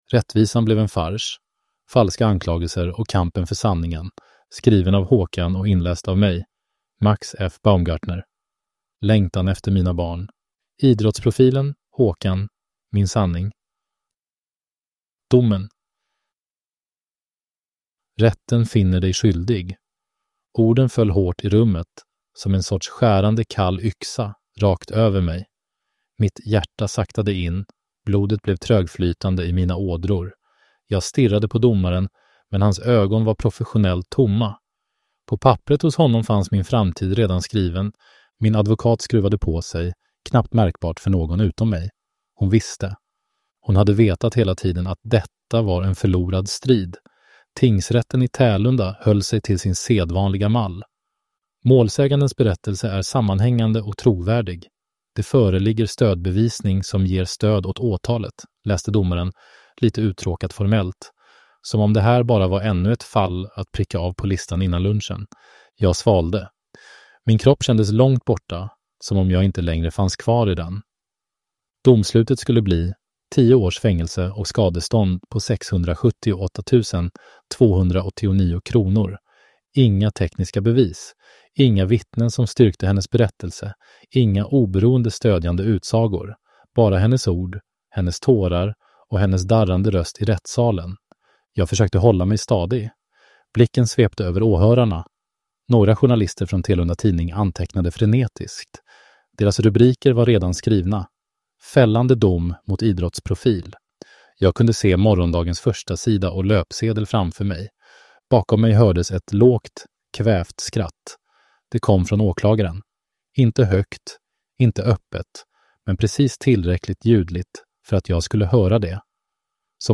/ Ljudbok